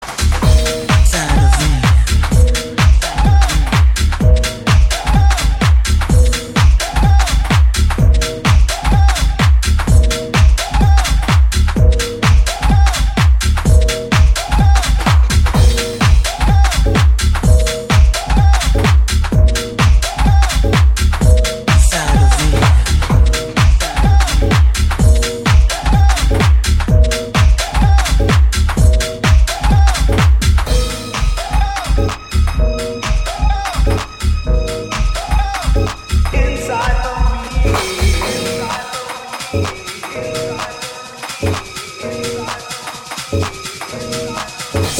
ノスタルジックなムードとブレイクで沸かせる